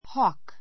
hawk hɔ́ːk ホ ー ク 名詞 鳥 タカ イメージ hawk 遠くからでも獲物 えもの を見つける鋭 するど い目を持っているので have eyes like a hawk （タカのような目を持っている）という表現がある.